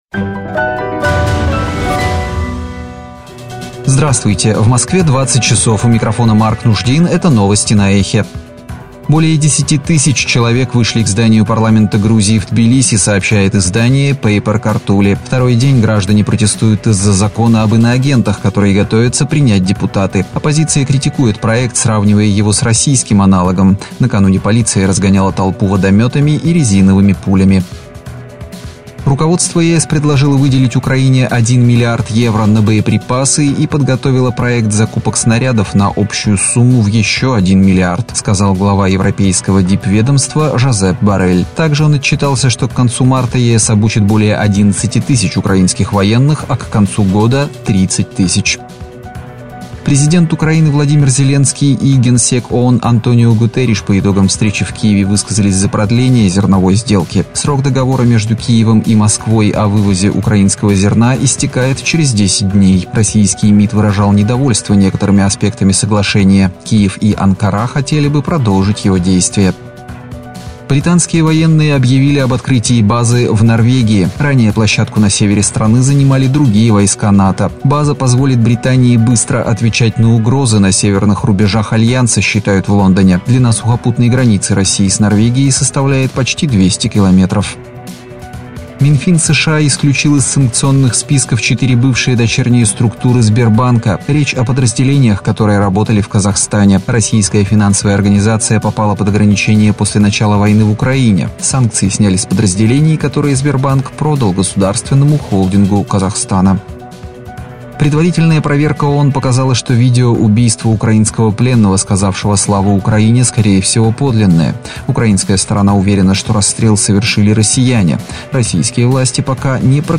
Слушайте свежий выпуск новостей «Эха»…
Новости